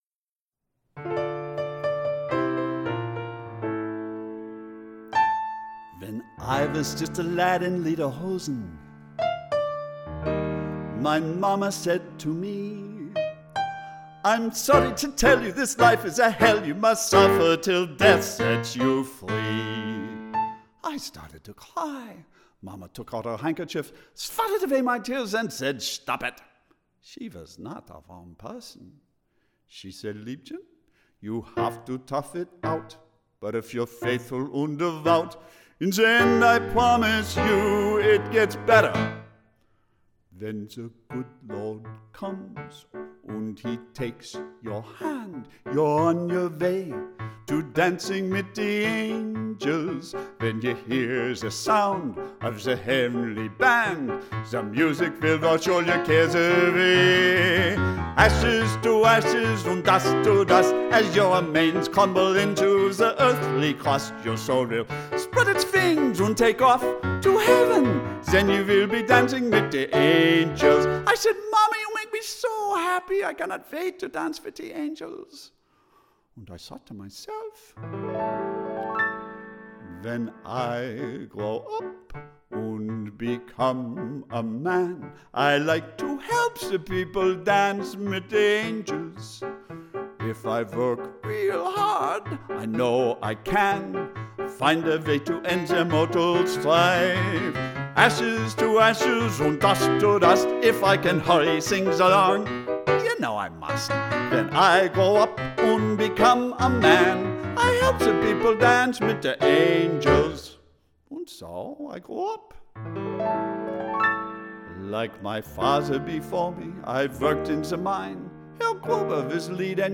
this is a darkly funny novelty number for Herr Winkelkopf